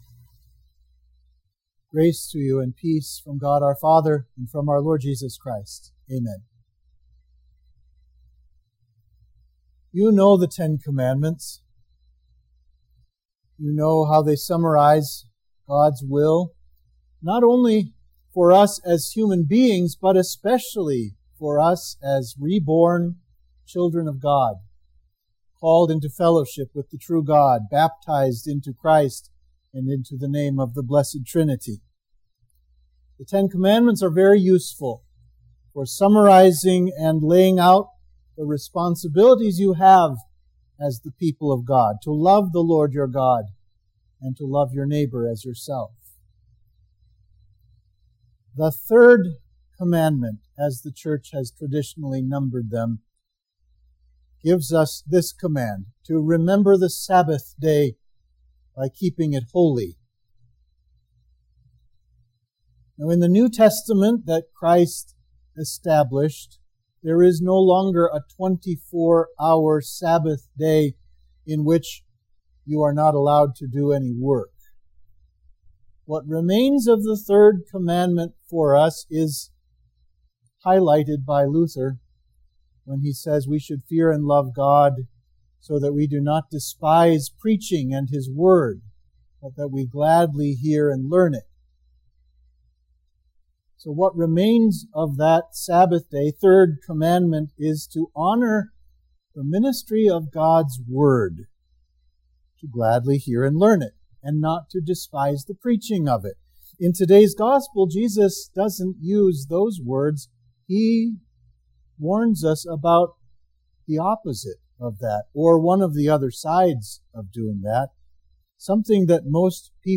Sermon for Trinity 8